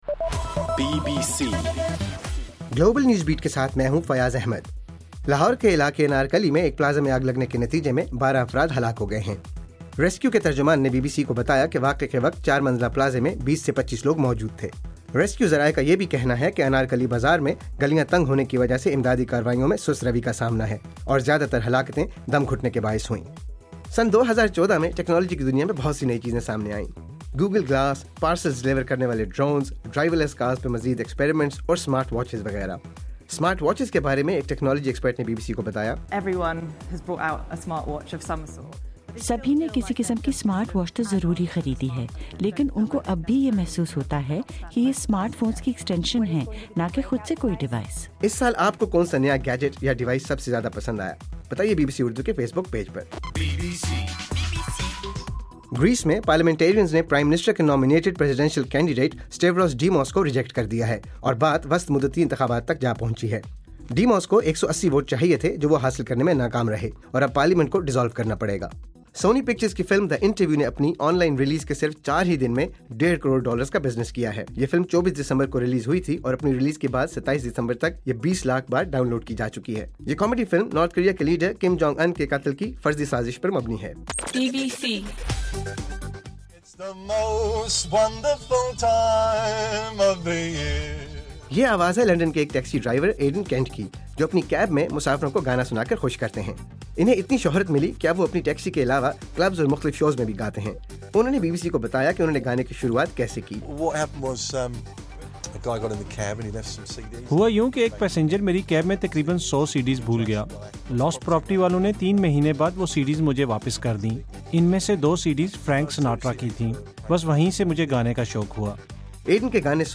دسمبر 29: رات 11 بجے کا گلوبل نیوز بیٹ بُلیٹن